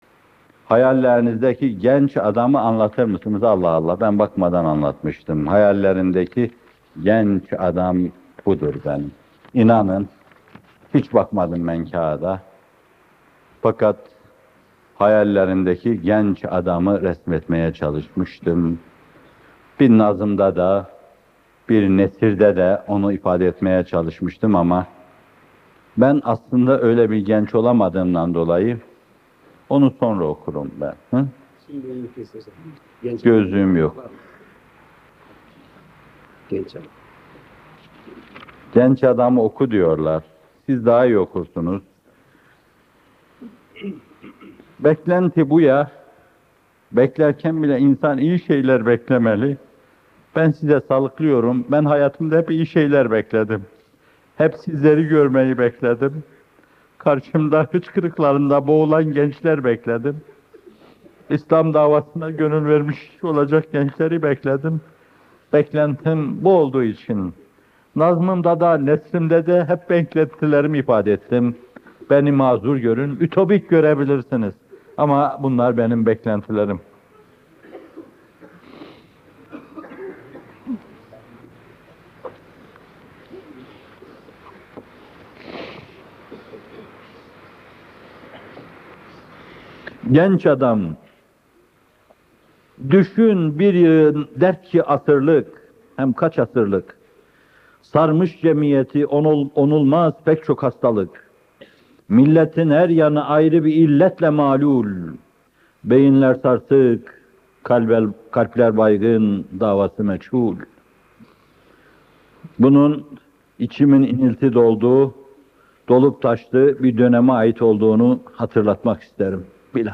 Muhterem Fethullah Gülen Hocaefendi, Samanyolu Koleji öğrencileriyle bir arada!
Muhterem Hocaefendi, Kırık Mızrap’tan “Genç Adam” şiirini okuyor.